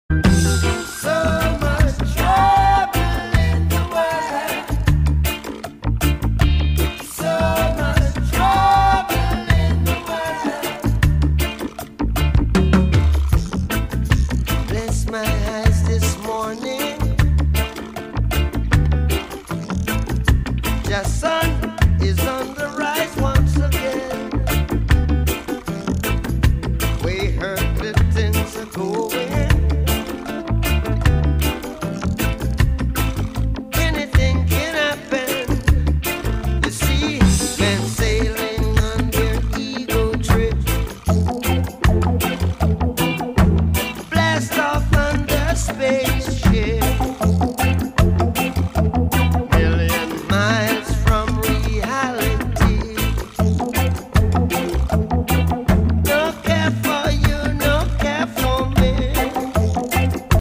🔥 Reggae meets revolution.